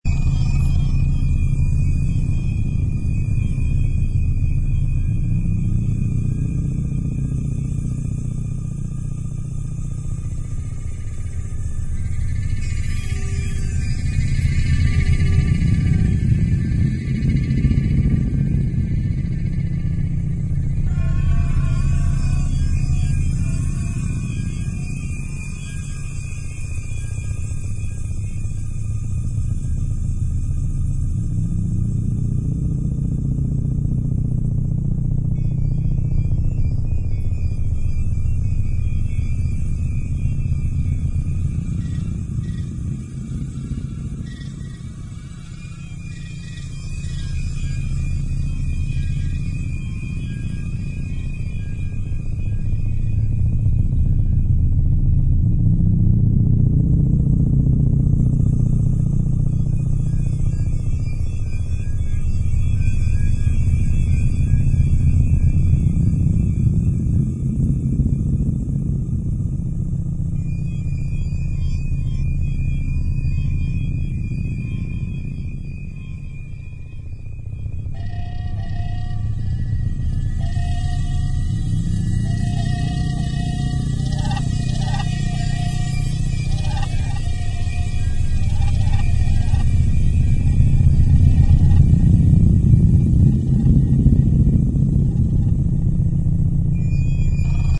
zone_field_mine.wav